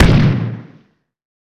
stomp.wav